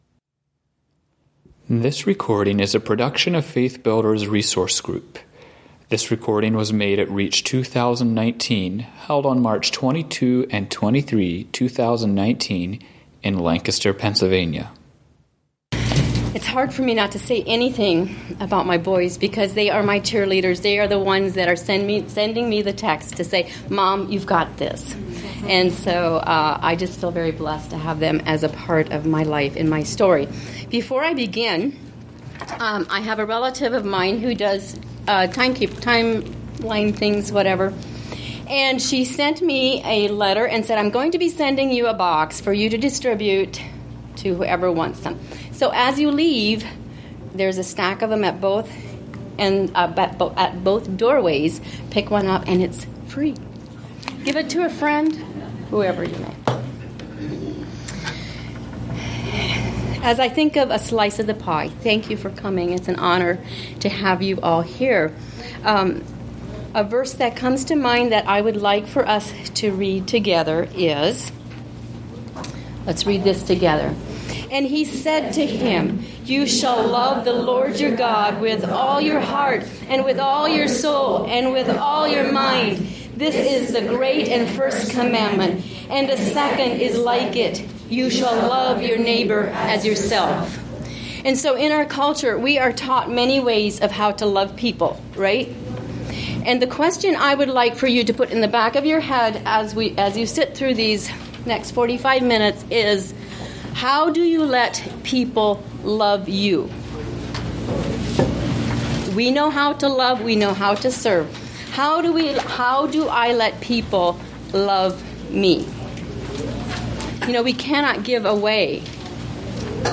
Home » Lectures » A Slice of the Pie